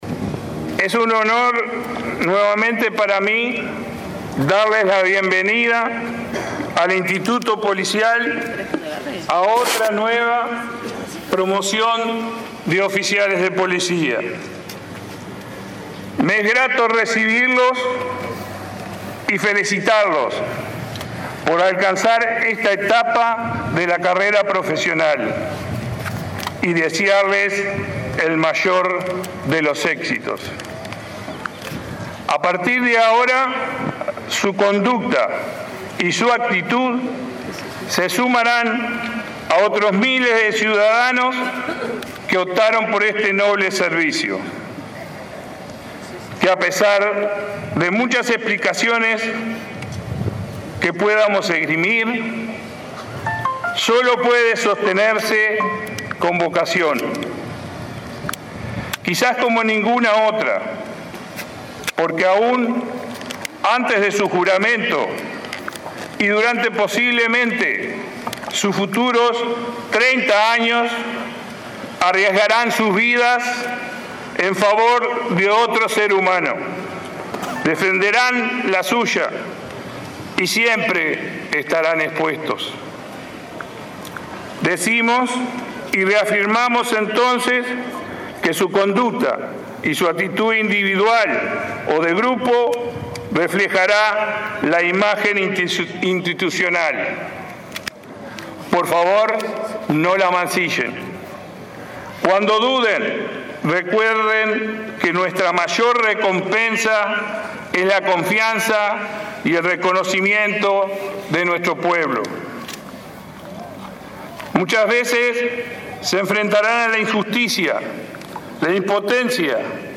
“No producimos criminales, la Policía los enfrenta en primera línea respetando la ley y los derechos humanos”, afirmó el director nacional de Policía, Mario Layera, durante la ceremonia de egreso de 121 oficiales ayudantes de la Policía Nacional. Se trata de la primera generación formada con la nueva currícula policial.